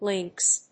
/líŋks(米国英語)/